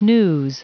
Prononciation du mot news en anglais (fichier audio)
Prononciation du mot : news